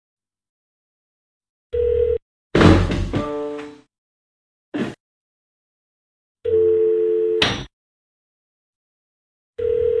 Звуки домофона